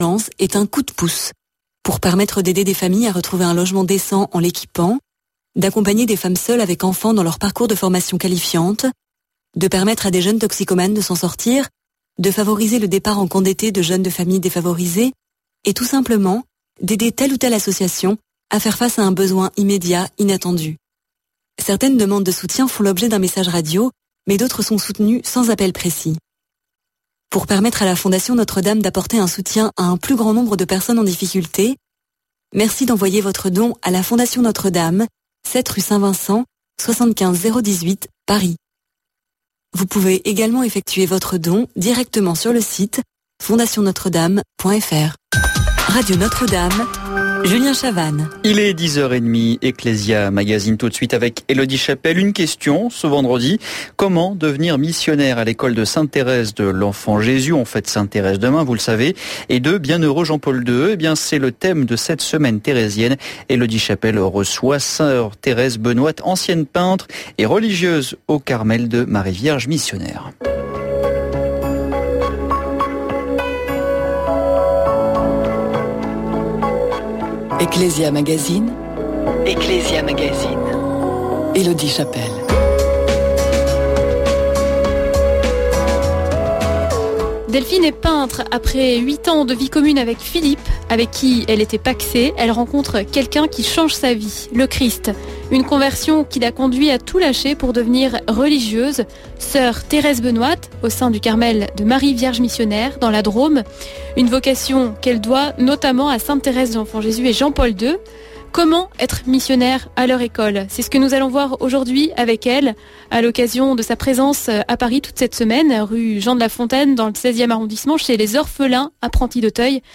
Interview exclusif